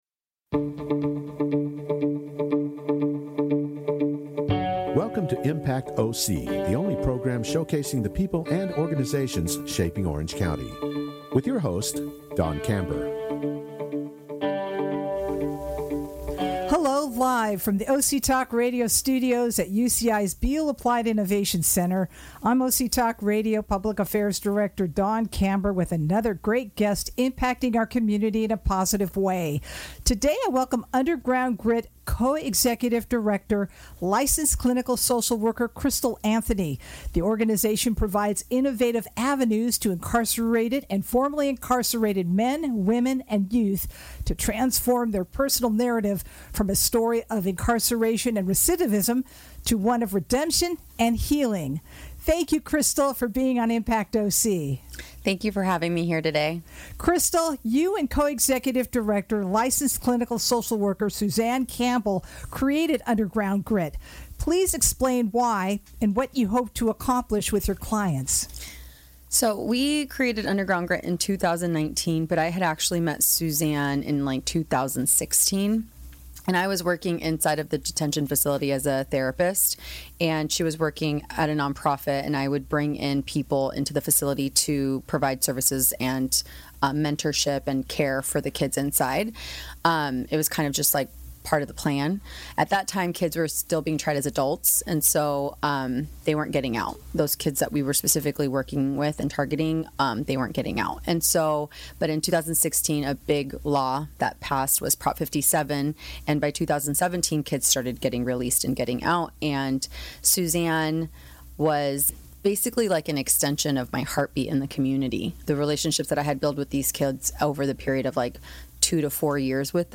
Only on OC Talk Radio, Orange County’s Only Community Radio Station which streams live from the University of California-Irvine’s BEALL APPLIED INNOVATION CENTER.